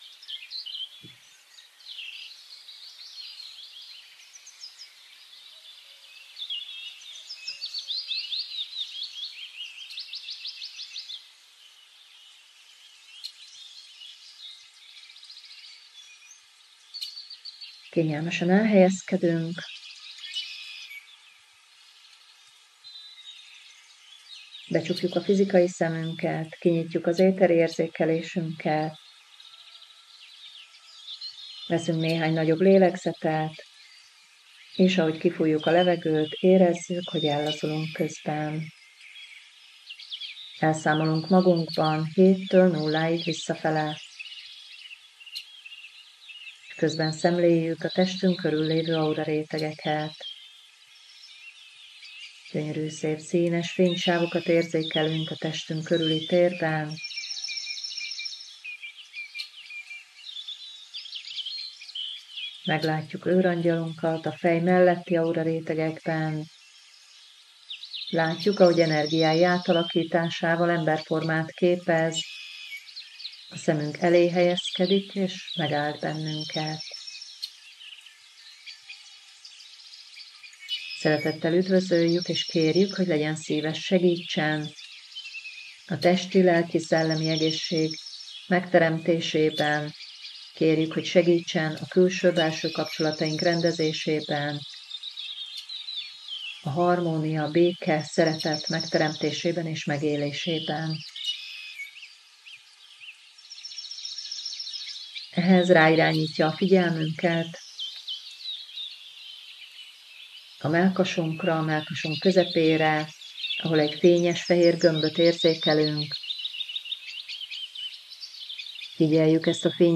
meditációt